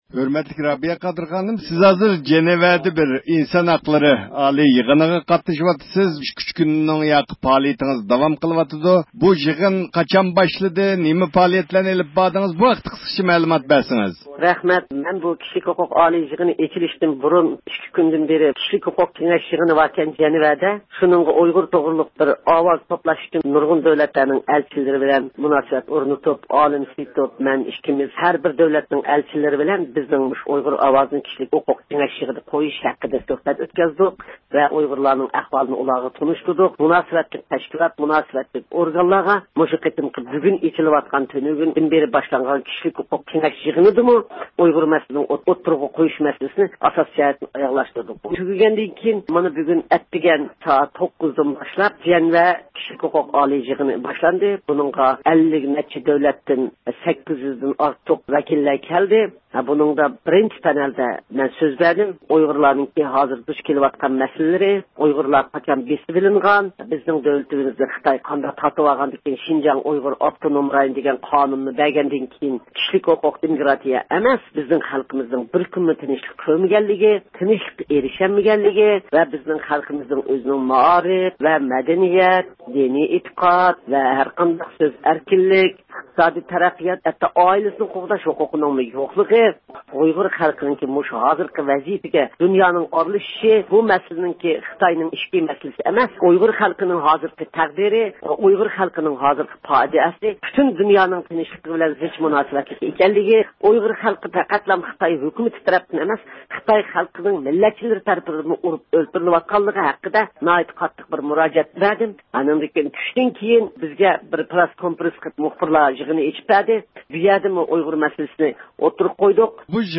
دۇنيا ئۇيغۇر قۇرۇلتىيىنىڭ رەئىسى رابىيە قادىر خانىم، شىۋىتسارىيىنىڭ پايتەختى جەنۋەدە ئېچىلغان «ئىنسان ھەقلىرى ئالىي يىغىنى»دا سۆز قىلدى.